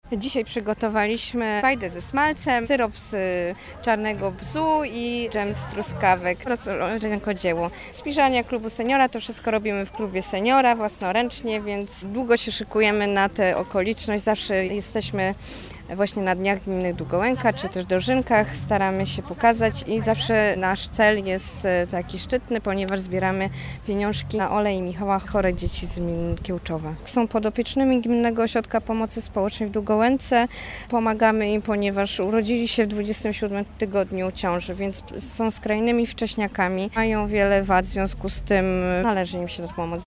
W Parku w Szczodrem odbył się piknik, dopisały frekwencja oraz pogoda.